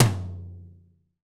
TOM TOM1802L.wav